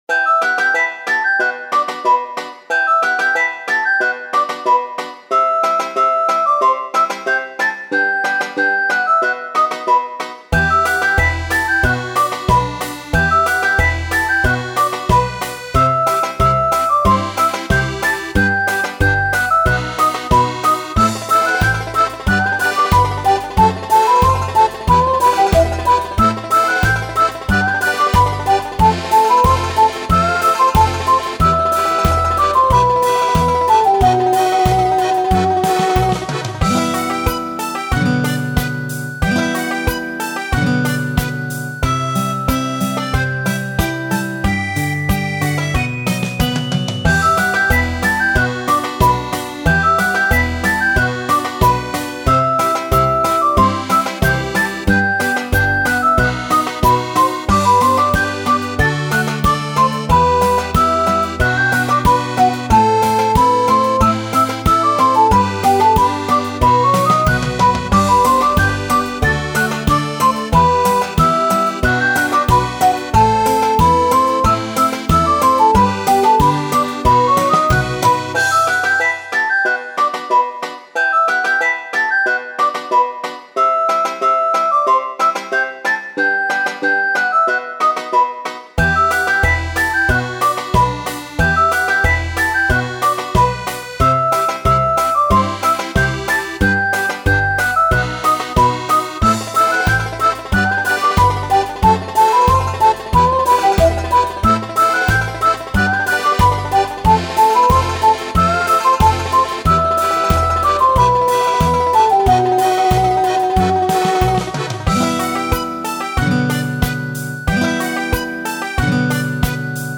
イメージ：異国情緒 郷愁   カテゴリ：RPG−街・村・日常